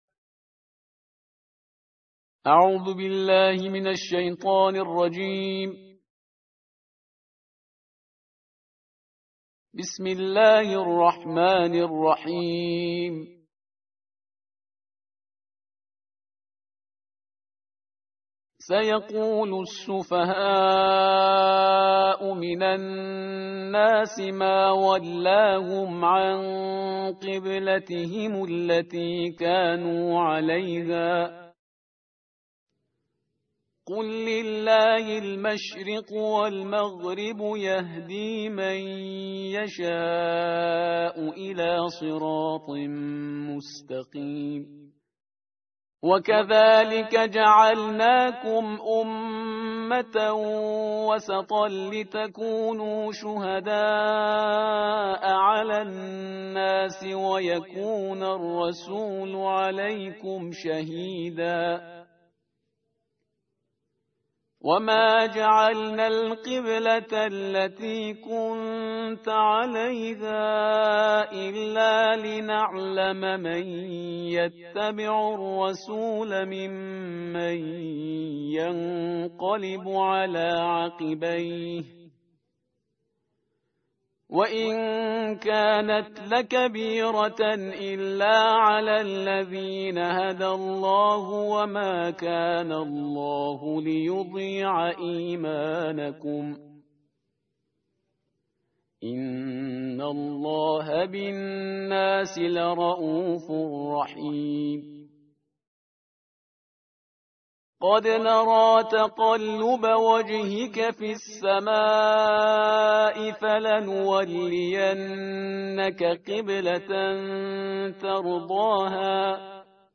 تلاوت ترتیل جزء دوم کلام وحی با صدای استاد